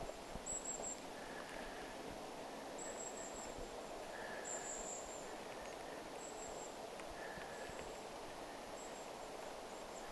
Äänite: pyrstötiaisparven innokasta ääntelyä
pyrstötiaisparven ääntelyä
kuusikossa
Niiden lennellessä eteen päin.
pyrstotiaisten_yhteysaania_niiden_liikkuessa_eteenpain.mp3